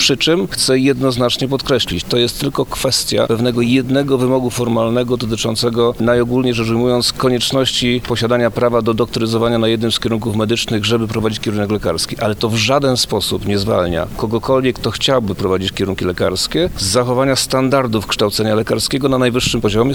O możliwościach uzupełnienia deficytu lekarzy na rynku pracy mówił w Lublinie minister edukacji i nauki Przemysław Czarnek.